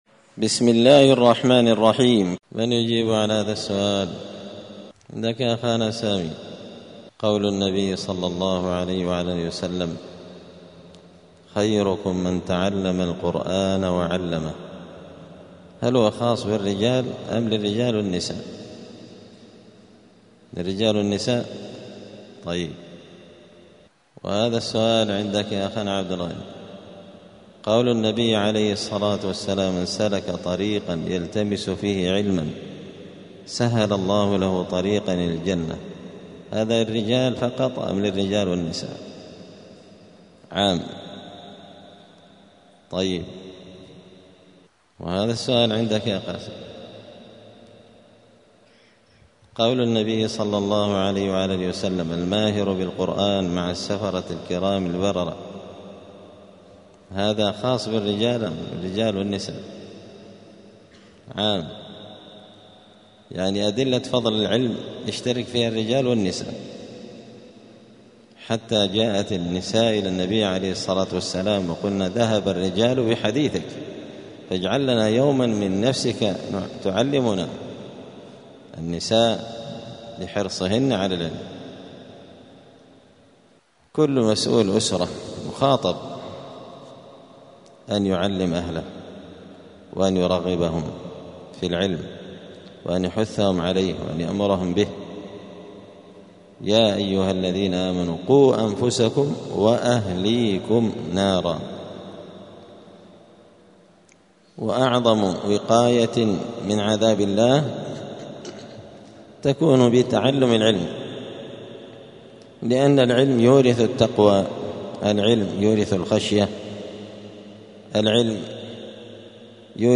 دار الحديث السلفية بمسجد الفرقان